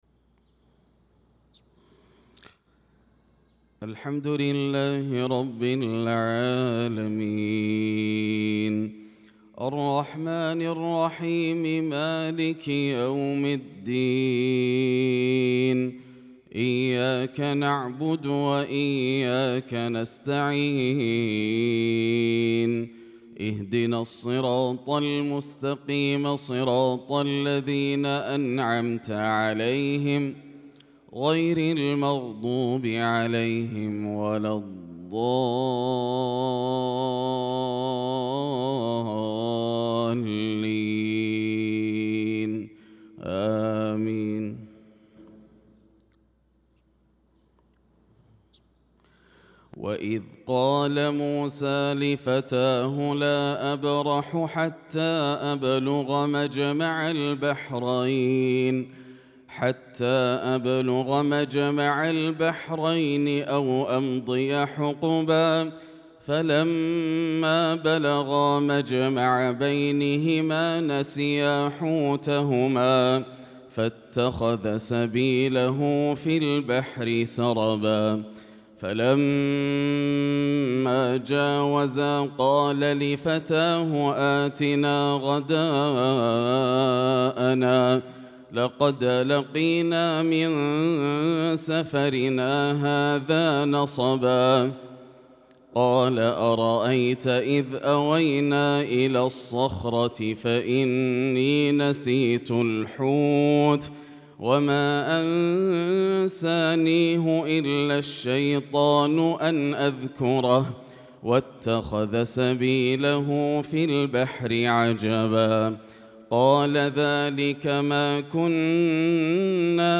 صلاة الفجر للقارئ ياسر الدوسري 9 ذو القعدة 1445 هـ
تِلَاوَات الْحَرَمَيْن .